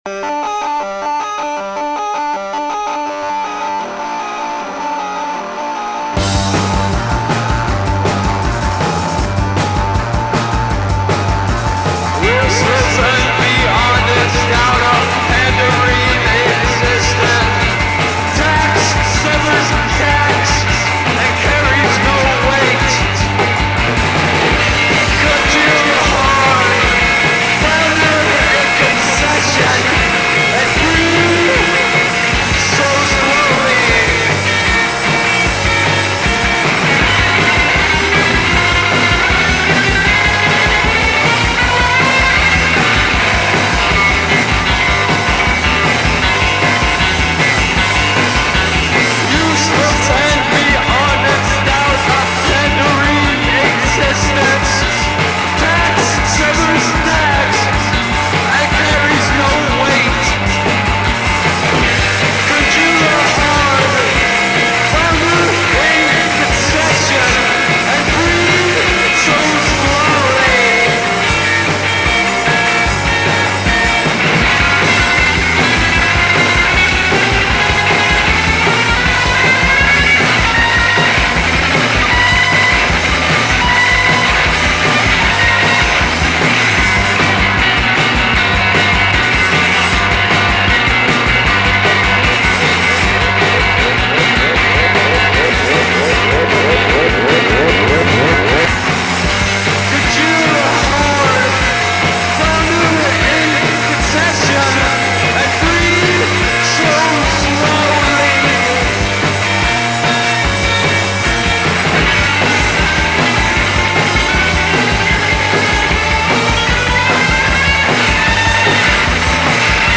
Sound infernale e malato, garage rock decomposto e oscuro.